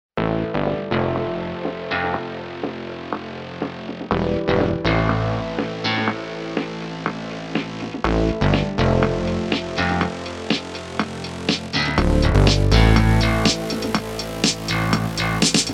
• Качество: 320, Stereo
поп
рэп
Мелодичный красивый рингтон
Поп, рэп